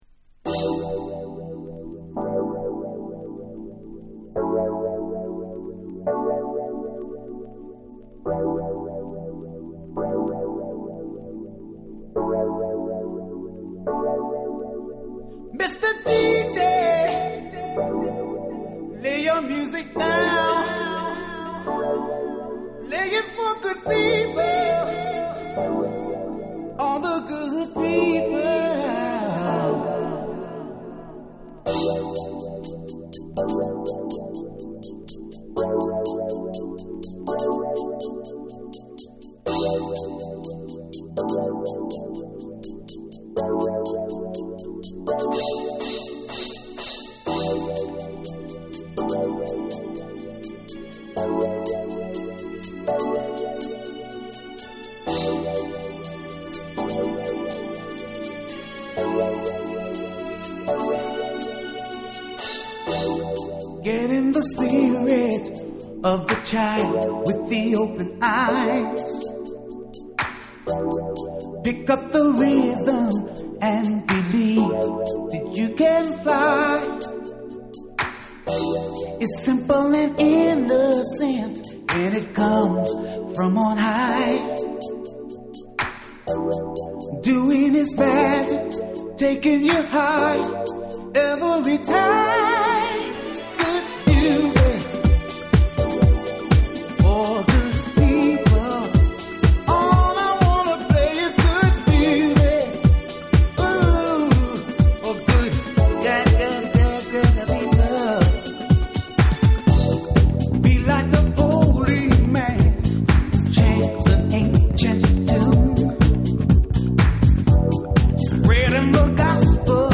日本のハウス・ミュージックDJ/音楽 プロデューサー
TECHNO & HOUSE / JAPANESE